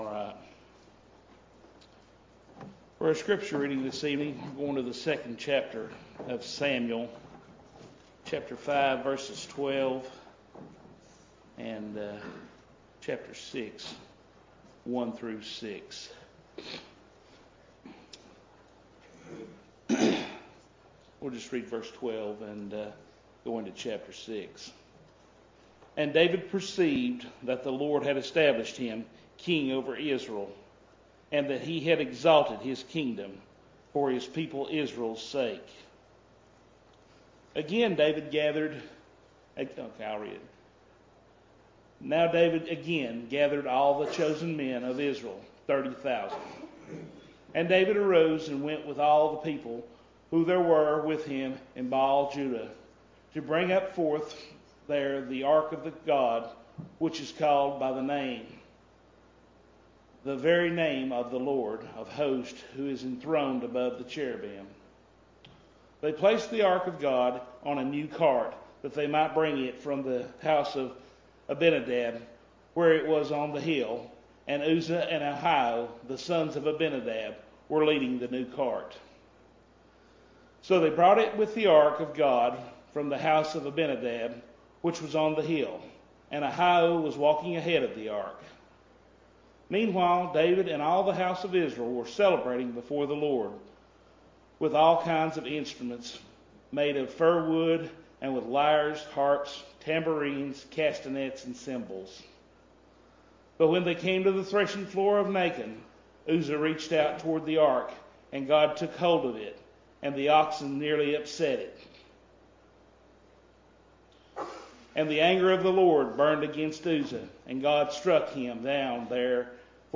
December 26, 2021 – Evening Worship